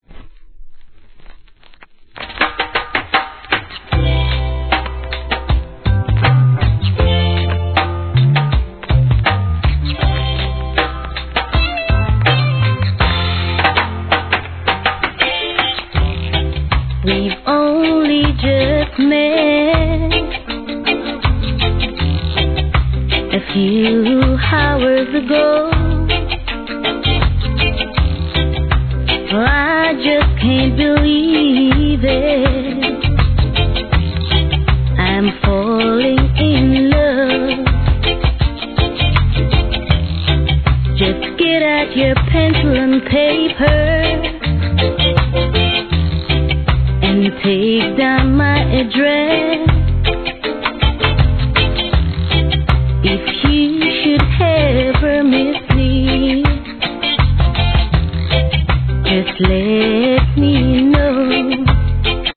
REGGAE
乾いたイントロのドラムブレイクから味があるフィメール・ヴォーカル物!